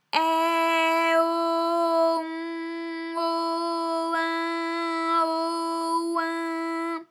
ALYS-DB-001-FRA - First, previously private, UTAU French vocal library of ALYS
ai_o_on_o_in_o_oin.wav